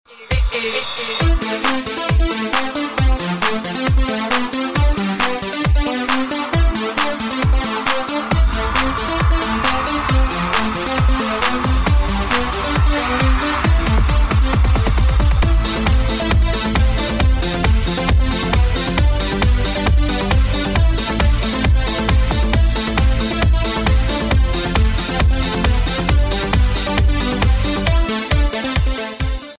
• Качество: 320, Stereo
Electronic
энергичные
Eurodance
Клубный eurodance ремикс